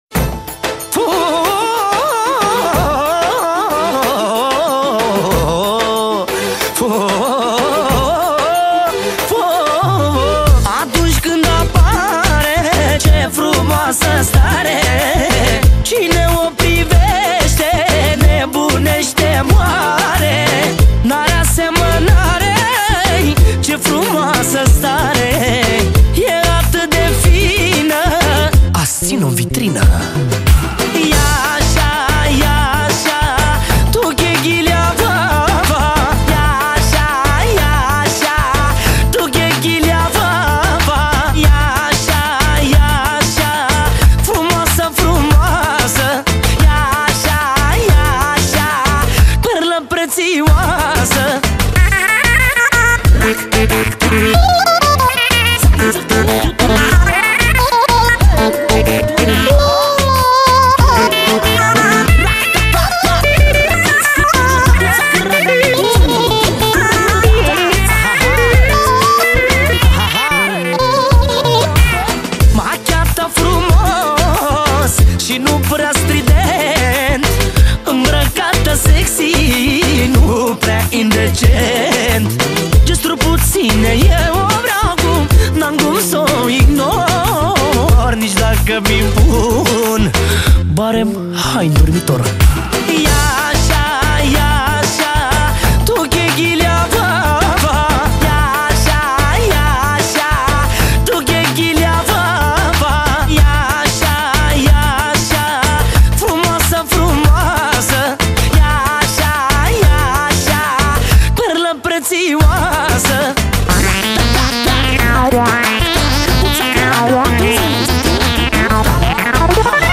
Data: 31.10.2024  Manele New-Live Hits: 0